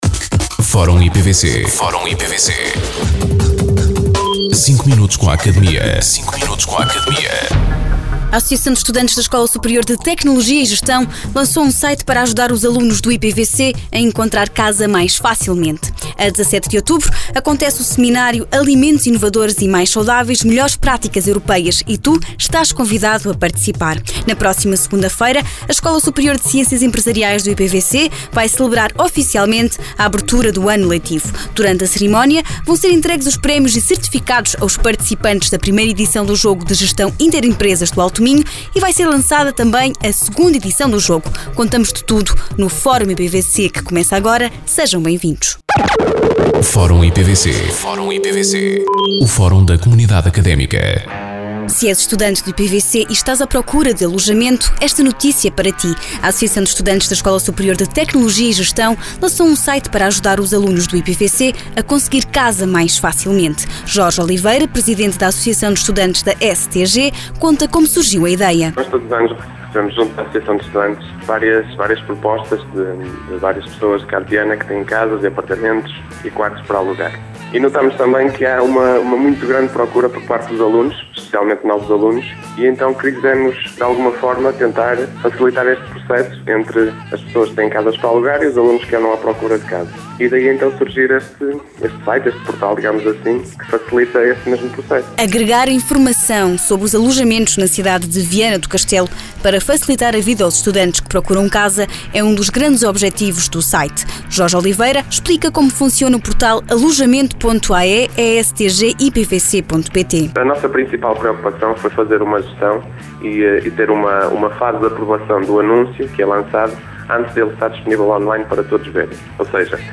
Entrevistados: